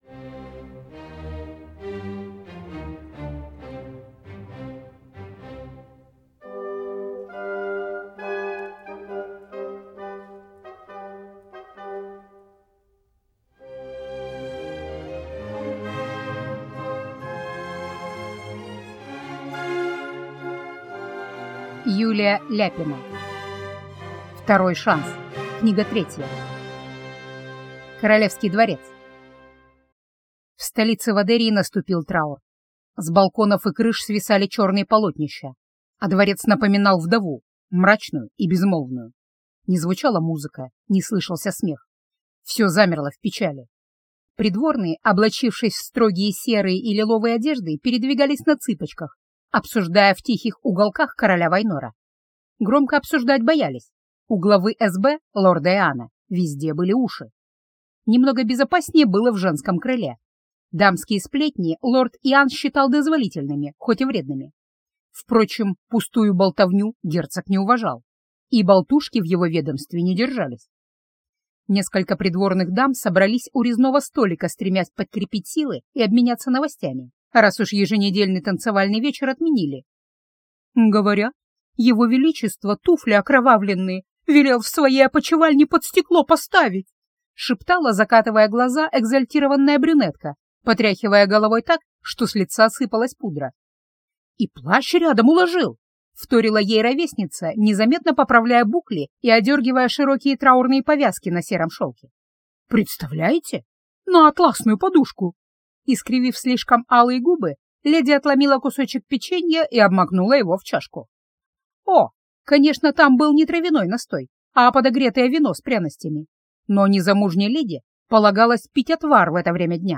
Аудиокнига Второй шанс. Книга 3